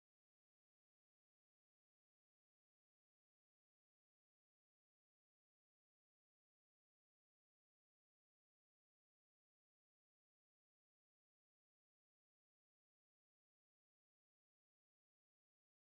Kinderlieder: Murmeltiers Reise
Tonart: F-Dur
Taktart: 2/4
Tonumfang: große Sexte